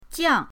jiang4.mp3